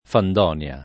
fandonia [ fand 0 n L a ]